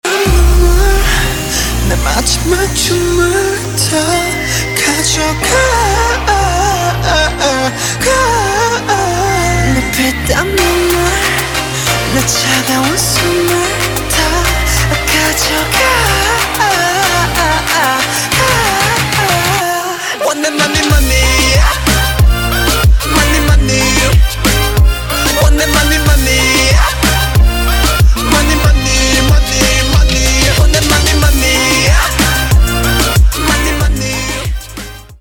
Pop & Rock
K-Pop